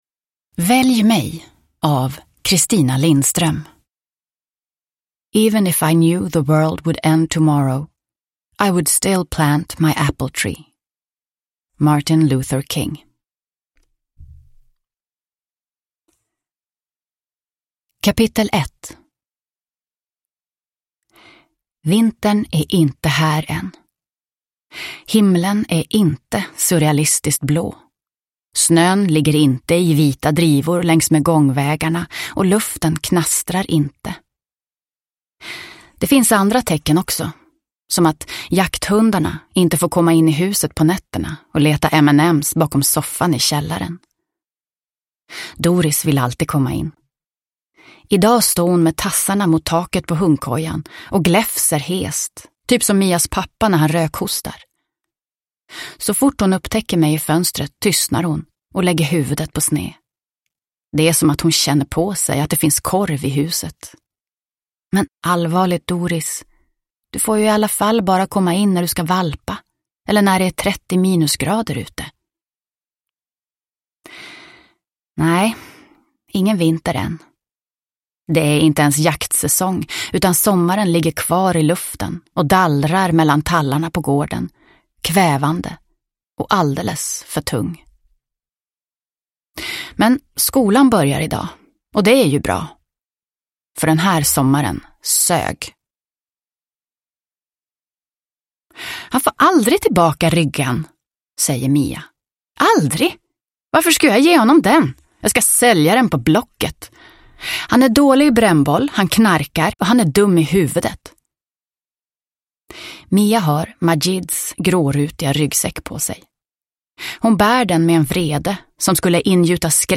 Välj mig – Ljudbok – Laddas ner